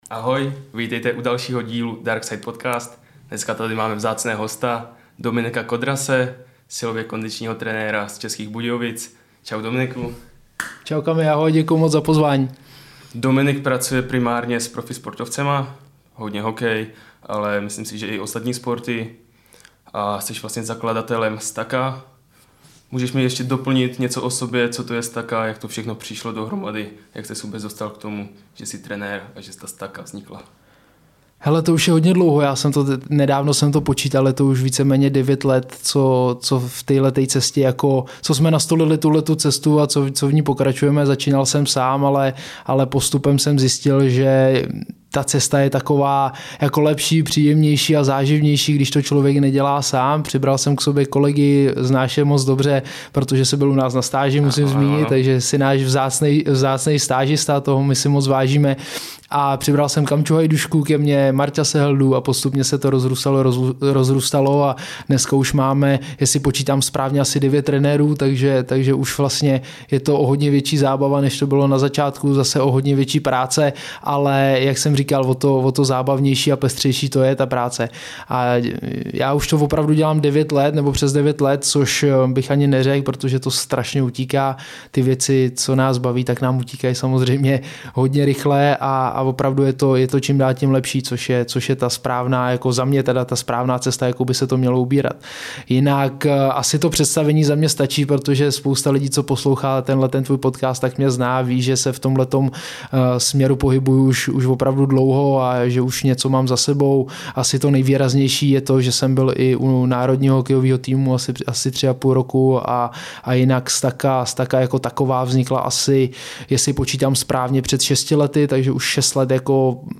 Během hodiny pohodového pokecu kluci probrali témata jako: